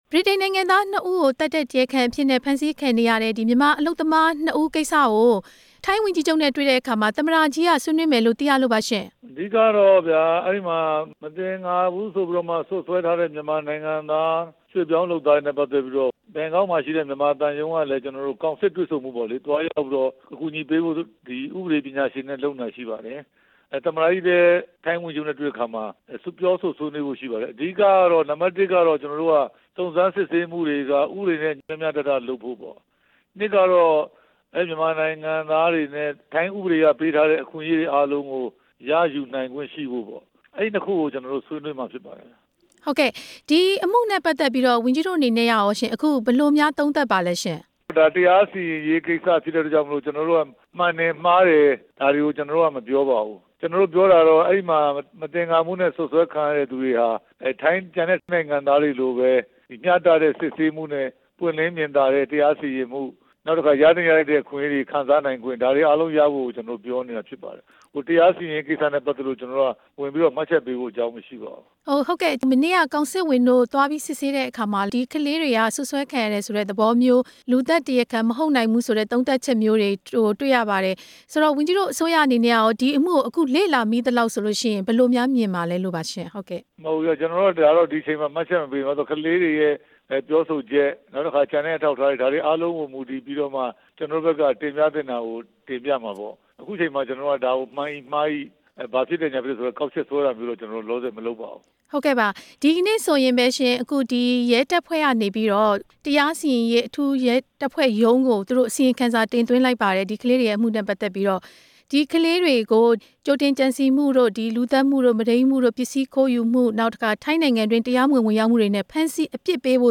၀န်ကြီး ဦးရဲထွဋ်ကို မေးမြန်းချက်